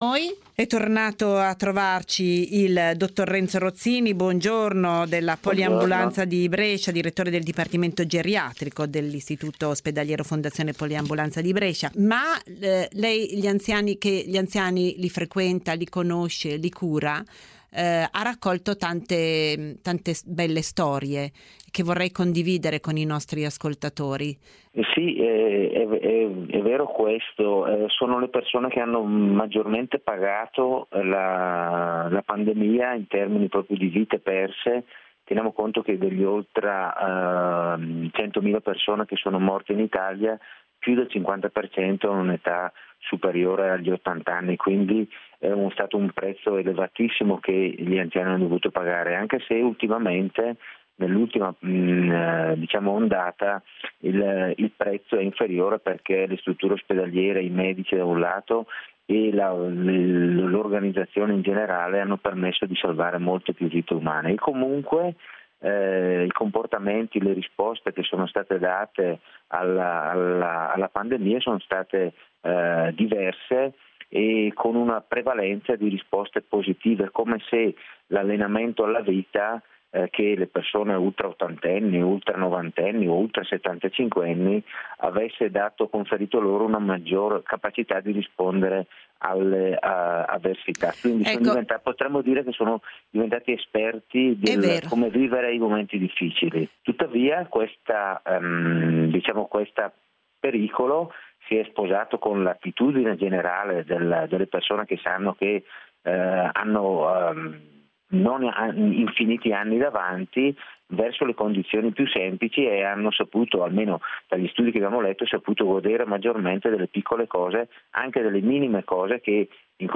a Radio 24 l'intervista